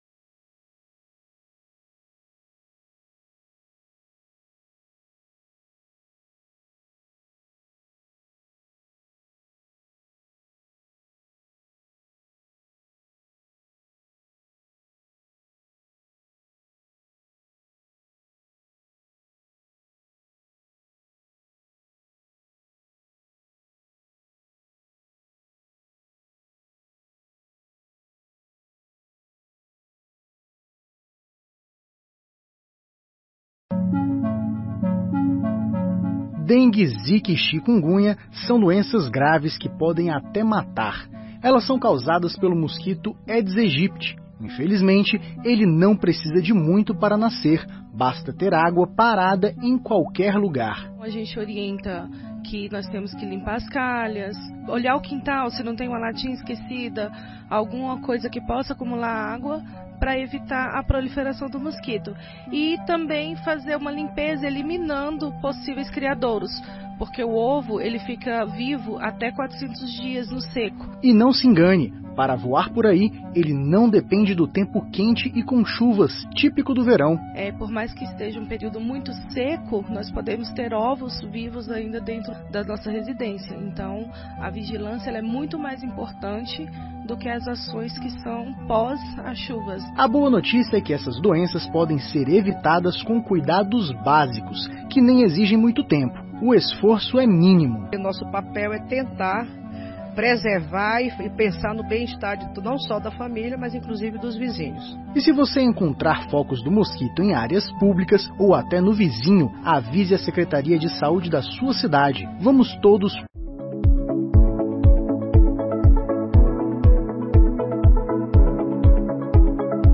11ª Sessão Ordinária de 2022